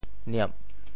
嗫（囁） 部首拼音 部首 口 总笔划 21 部外笔划 18 普通话 niè 潮州发音 潮州 nieb4 文 中文解释 嗫嚅 [speak haltingly] 想说而又吞吞吐吐不敢说出来 口将言而嗫嚅。